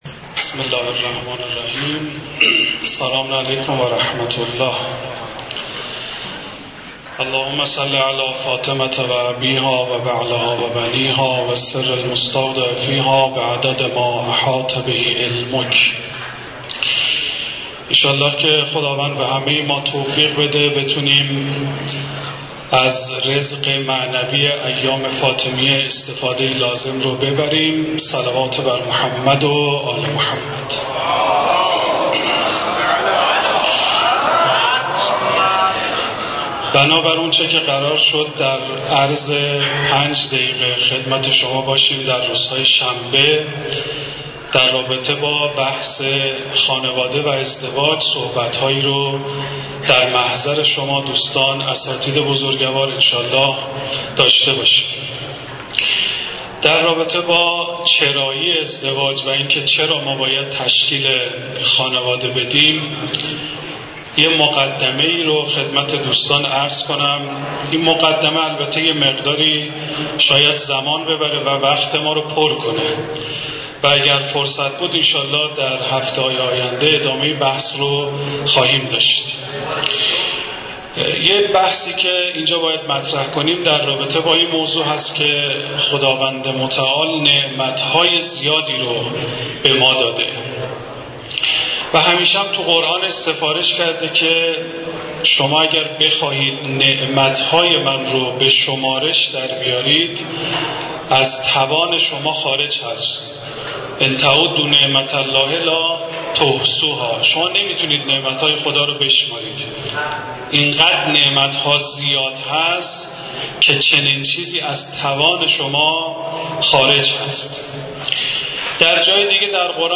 بیان مباحث مربوط به خانواده و ازدواج در کلام مدرس حوزه و دانشگاه در مسجد دانشگاه کاشان